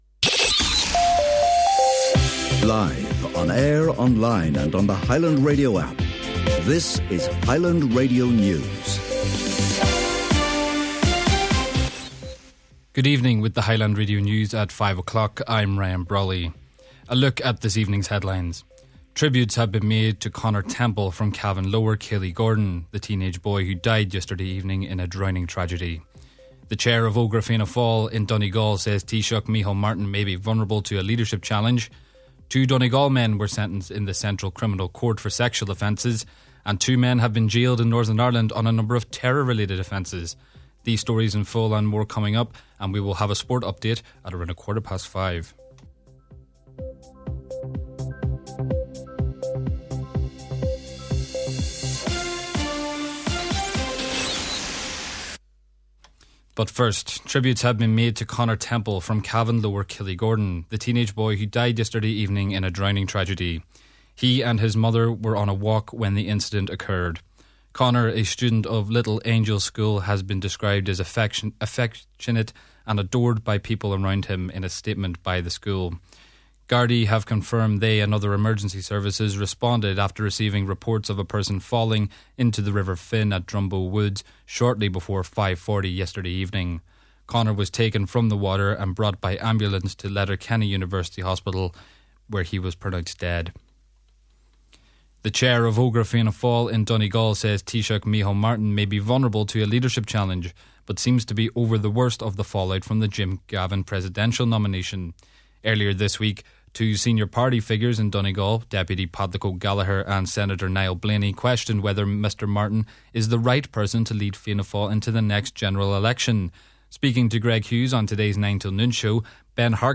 Main Evening News, Sport, An Nuacht & Obituary Notices – Friday December 19th